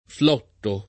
flotto [ fl 0 tto ]